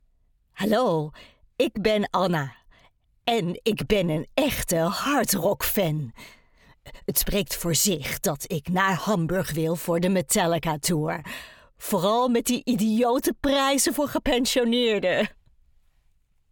Seguro, Amable, Empresarial, Comercial, Accesible, Cálida